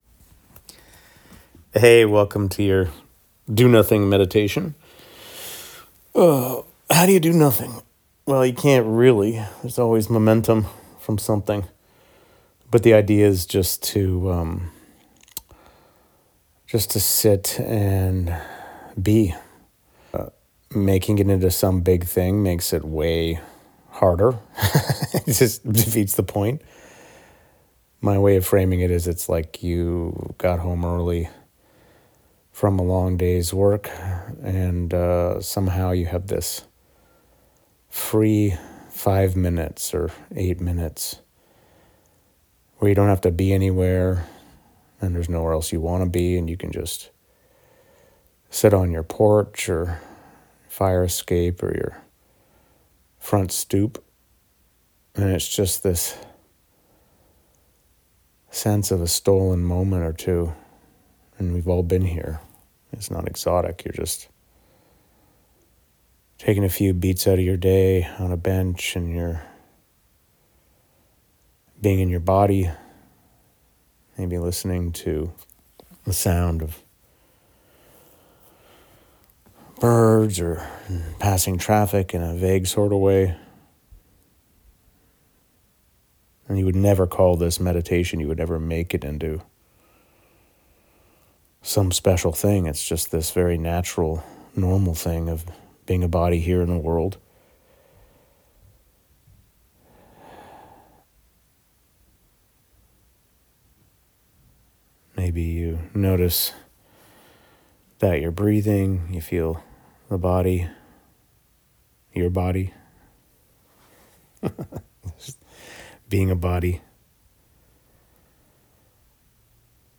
If you don’t want to read any of this, here’s a 7 minute do nothing meditation:
DoNothingMeditation-7min.mp3